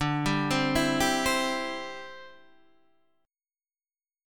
D7sus2sus4 chord {x 5 5 5 5 8} chord